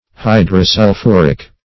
Search Result for " hydrosulphuric" : The Collaborative International Dictionary of English v.0.48: Hydrosulphuric \Hy`dro*sul*phu"ric\, a. [Hydro-, 2 + sulphuric.]
hydrosulphuric.mp3